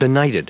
Prononciation du mot benighted en anglais (fichier audio)
benighted.wav